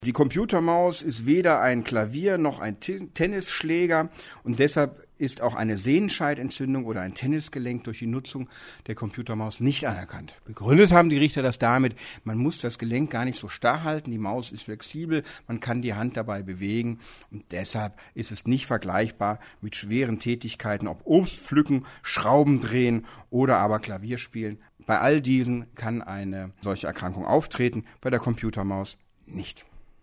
O-Ton: Keine Berufskrankheit wegen Computermaus